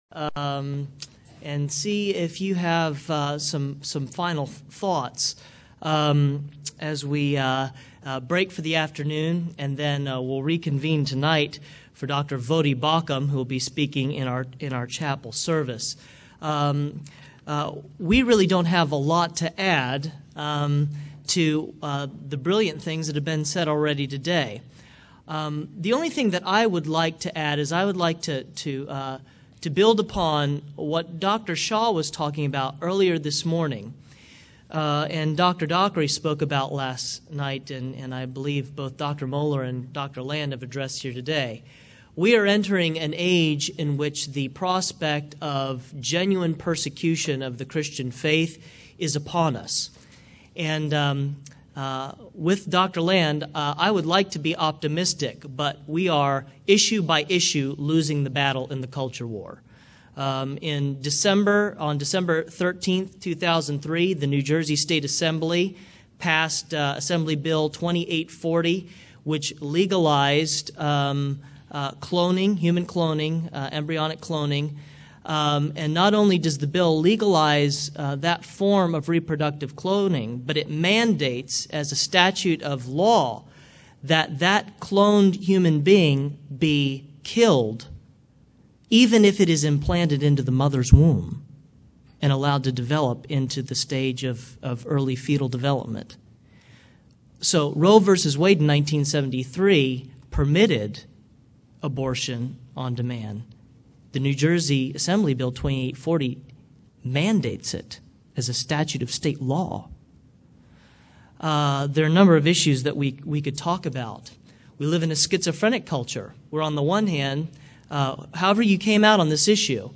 Baptist Identity Conference